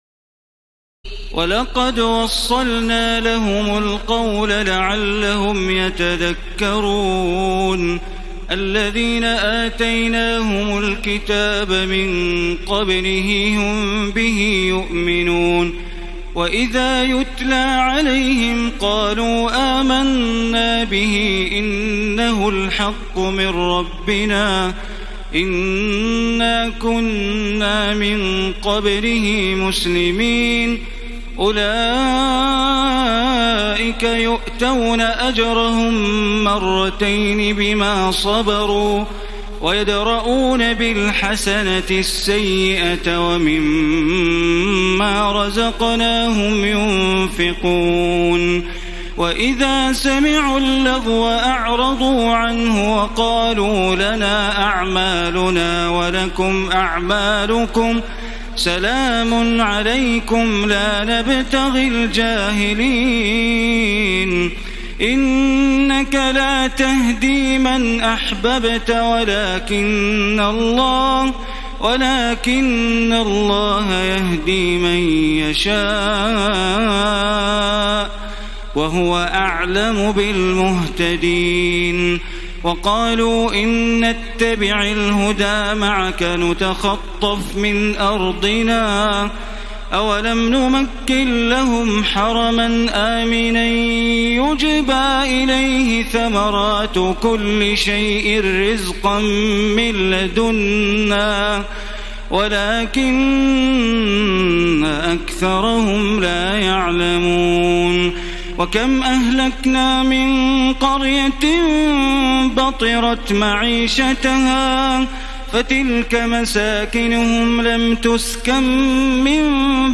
تراويح الليلة التاسعة عشر رمضان 1434هـ من سورتي القصص (51-88) والعنكبوت (1-45) Taraweeh 19 st night Ramadan 1434H from Surah Al-Qasas and Al-Ankaboot > تراويح الحرم المكي عام 1434 🕋 > التراويح - تلاوات الحرمين